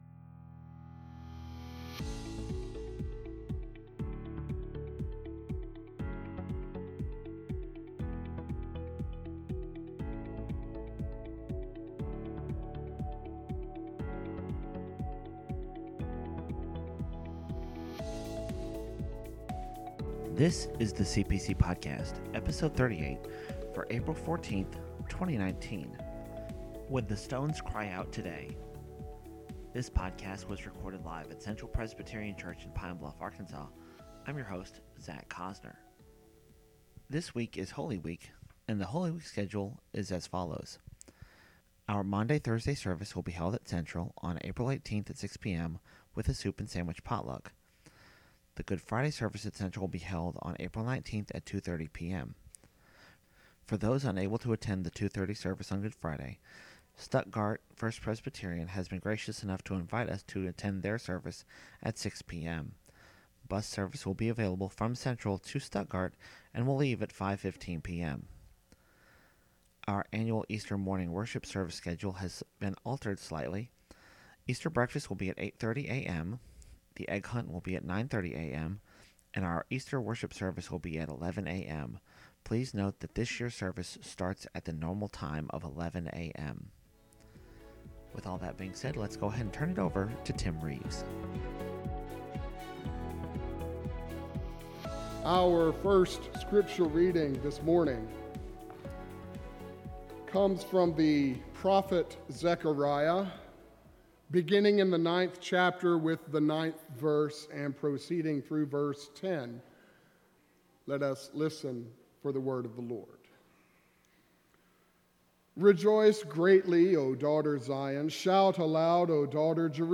The Palm Sunday sermon is available here.